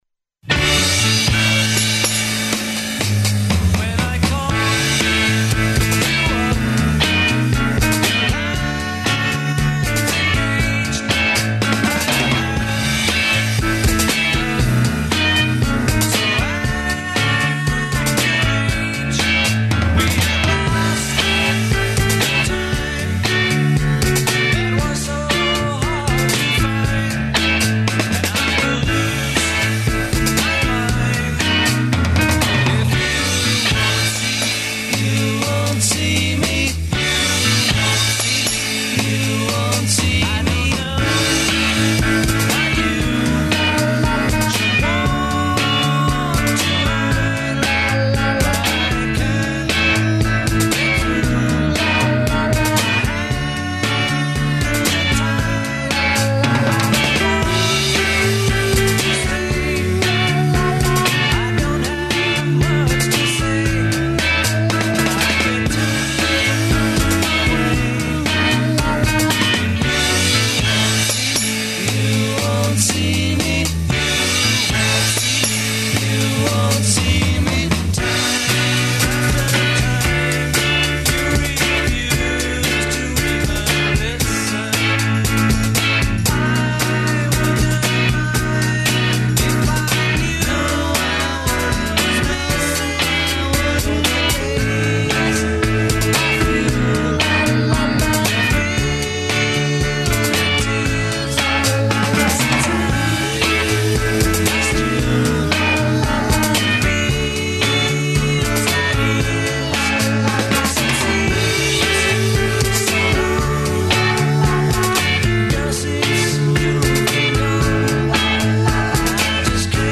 Емисију уживо емитујемо из Нове Вароши.